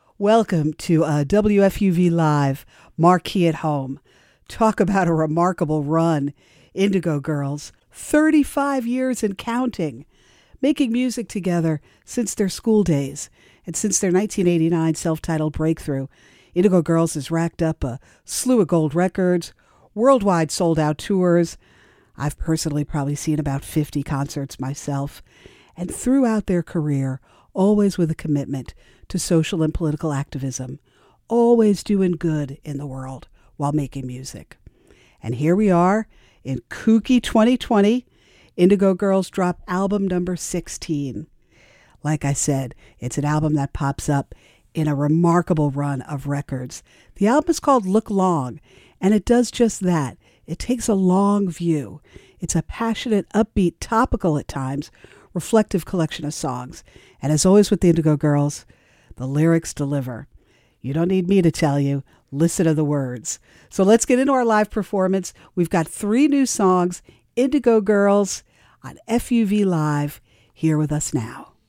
(captured from webstream)